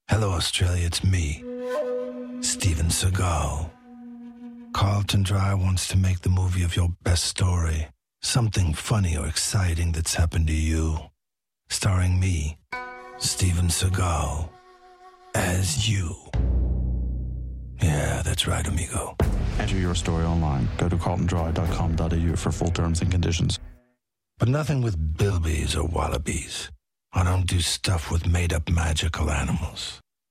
As well as TV promotion, the campaign also features on radio.